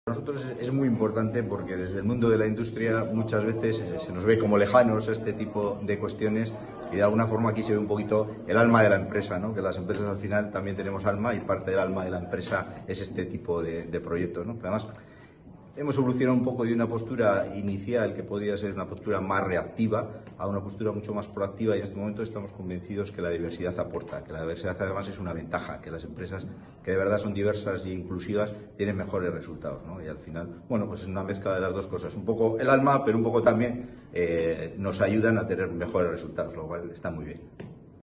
El auditorio Mitxelena, del Bizkaia Aretoa en Bilbao, sirvió de escenario para que la Organización agradeciera y homenajeara a toda la sociedad vasca por la solidaria actitud con la que día a día le apoya a través de la compra de sus productos de juego responsable.